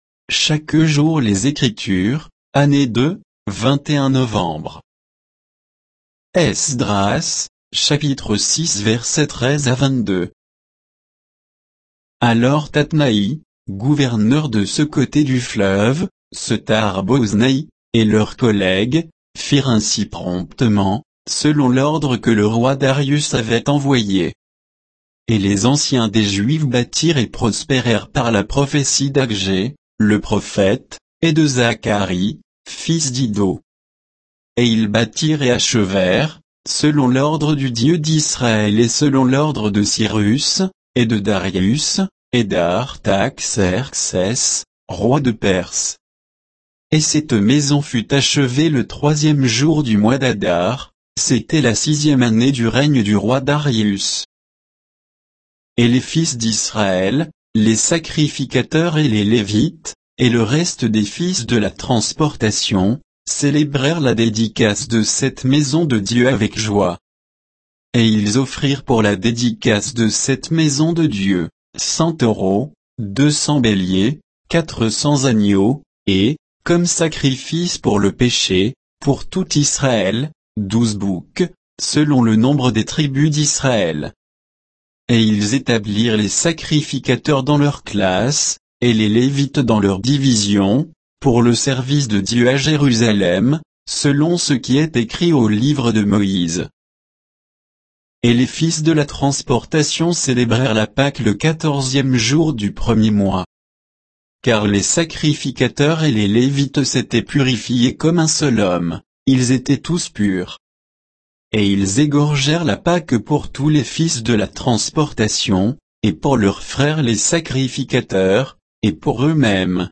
Méditation quoditienne de Chaque jour les Écritures sur Esdras 6